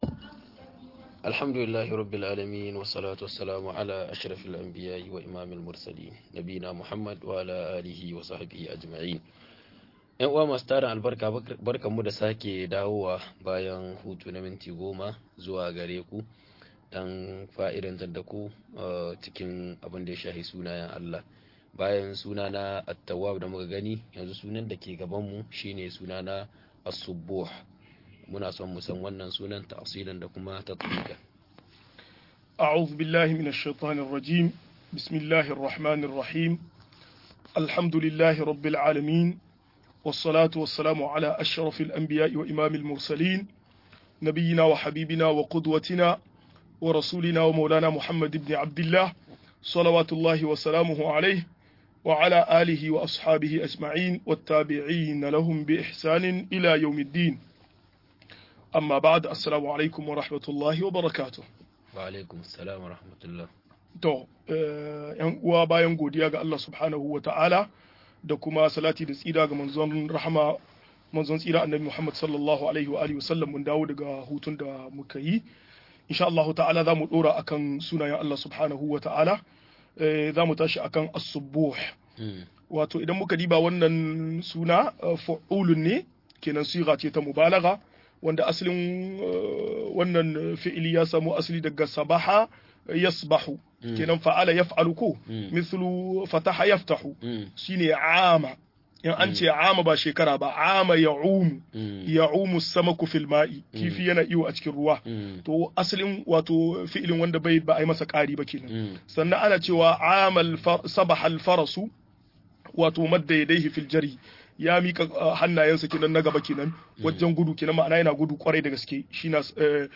Sunayen Allah da siffofin sa-22 - MUHADARA